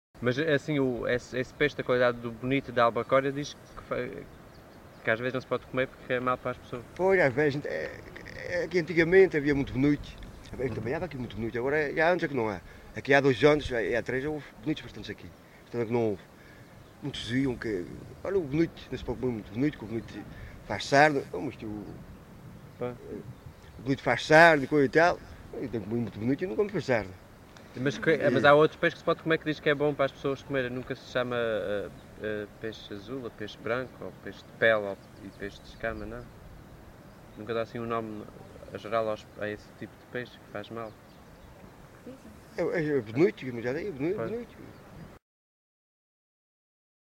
LocalidadeSanta Cruz da Graciosa (Santa Cruz da Graciosa, Angra do Heroísmo)